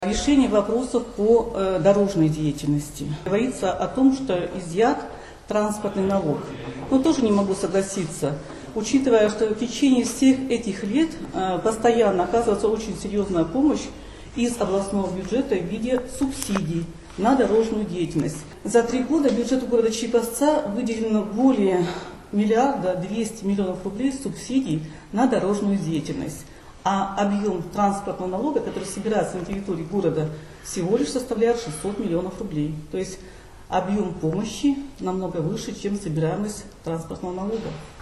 Заместитель губернатора также опровергла слова о том, что область отбирает у Череповца транспортный налог, объяснив это следующим образом.